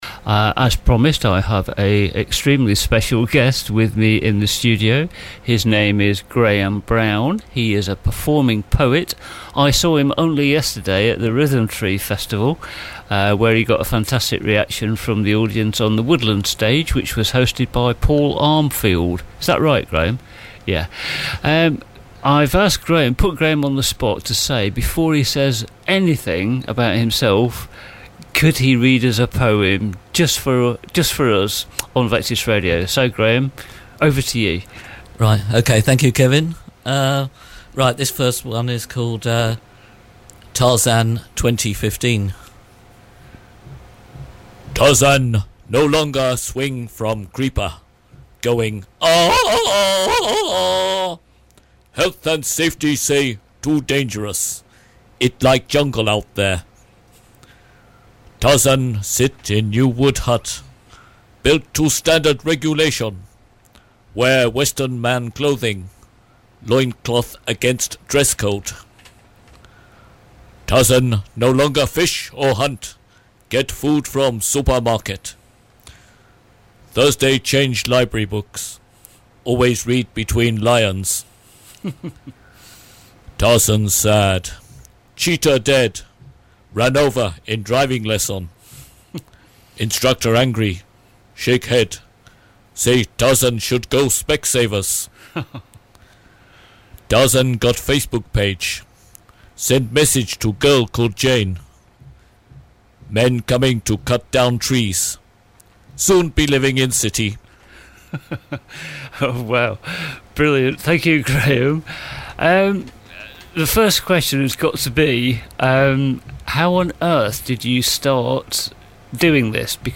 Performance Poet
talks, about his work, and reads poems